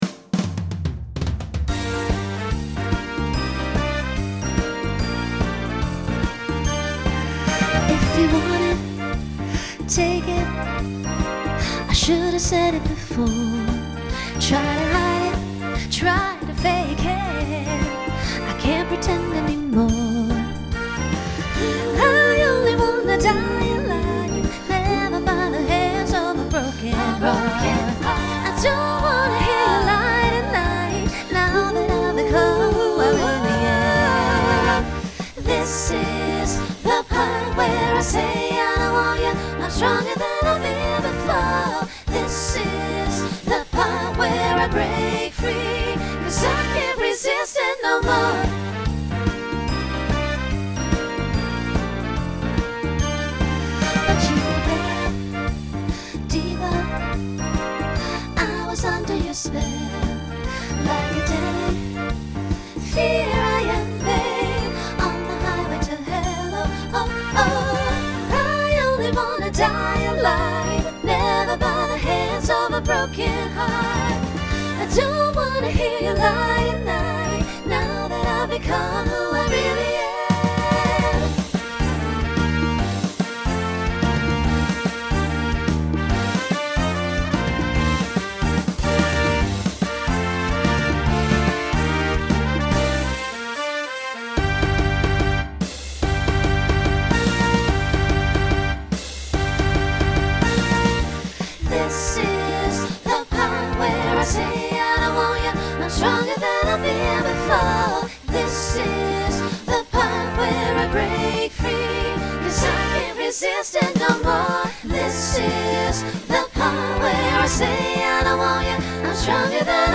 Voicing SSA Instrumental combo Genre Swing/Jazz
Mid-tempo